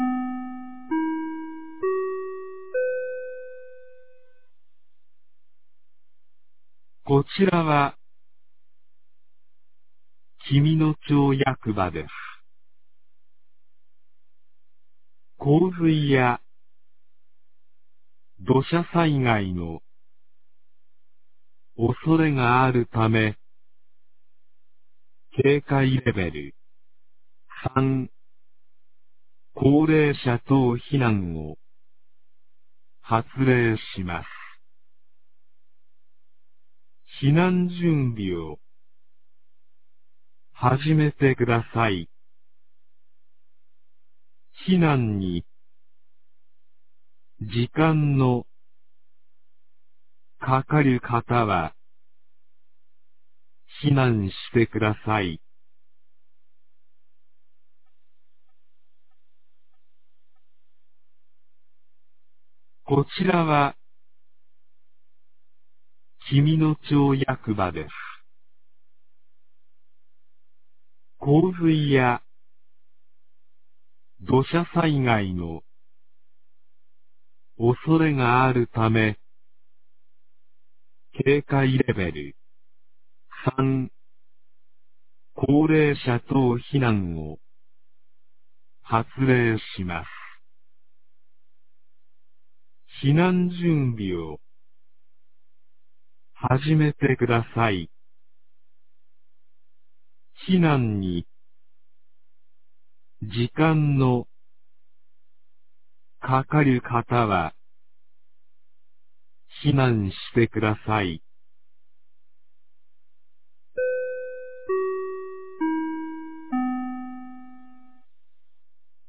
2023年06月02日 11時23分に、紀美野町より全地区へ放送がありました。
放送音声